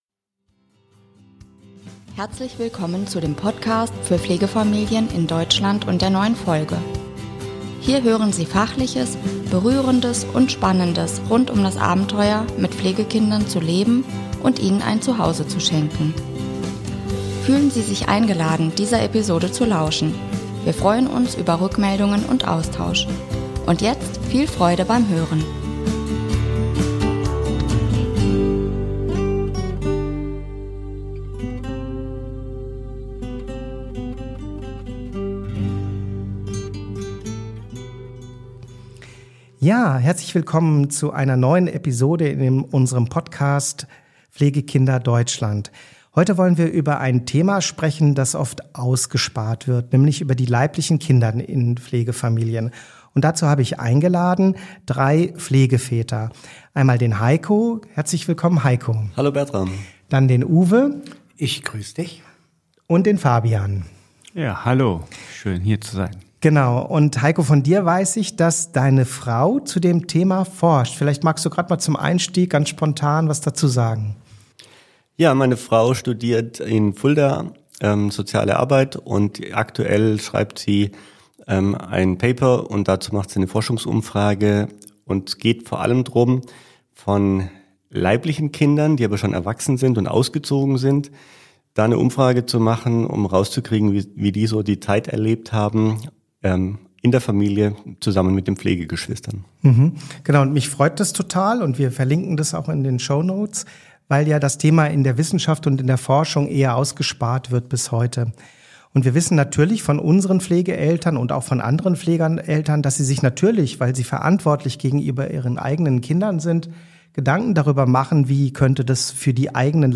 Die Aufnahme heute entsteht bei unserem Pflegeväterwochenende.